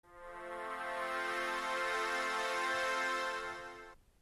Audio kvíz:
• Některé pomůcky nás po spuštění vítají charakteristickým zvukem nebo znělkou. Hádejte, která pomůcka oznamovala tisícům nevidomých po celém světě včetně Česka, že je připravena k použití, pomocí typického akordu, který je slyšet v ukázce.